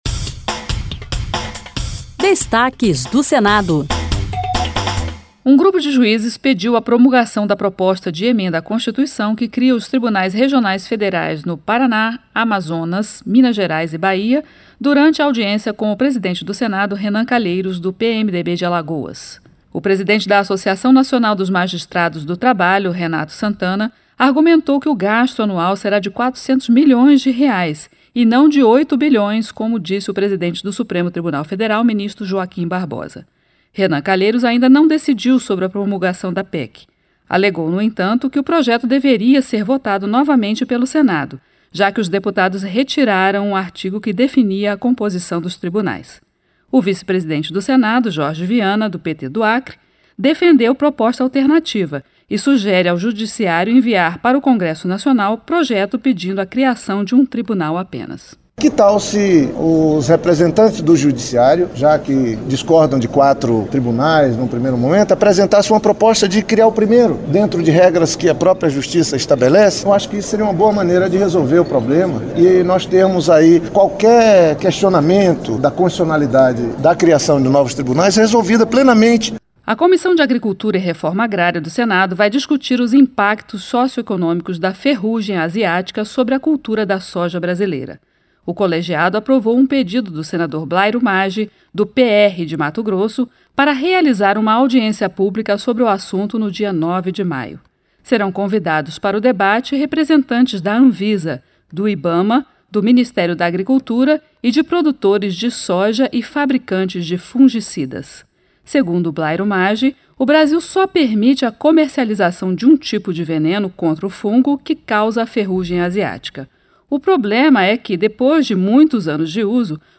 Entrevistas regionais, notícias e informações sobre o Senado Federal